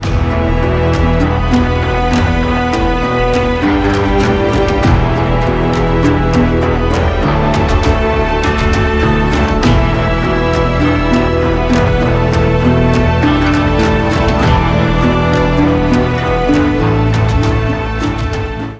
• Качество: 192, Stereo
ковбойская тема
эпичные
из игры
дикий запад